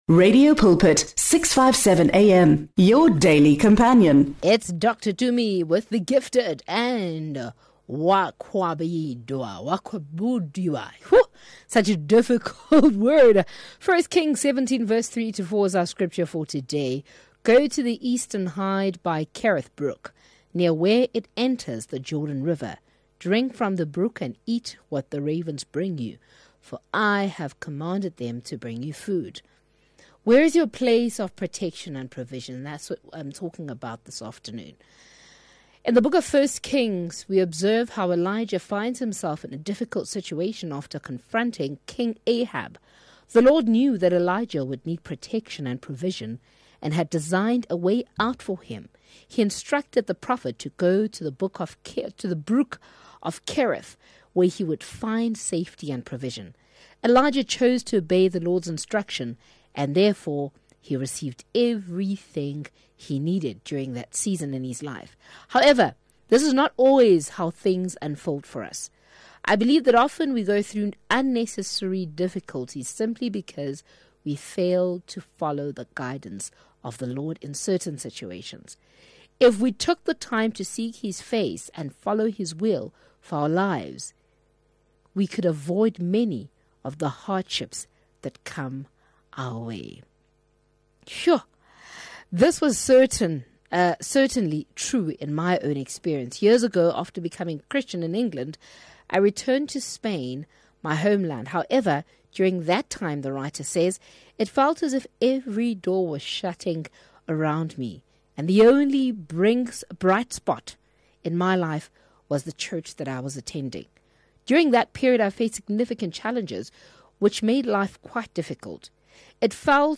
They focus on empowering young people to regain control over their lives instead of succumbing to gaming and internet addictions. Additionally, they provide guidance for parents on how to support their children in overcoming these challenges. Their conversation aims to raise awareness and offer practical solutions.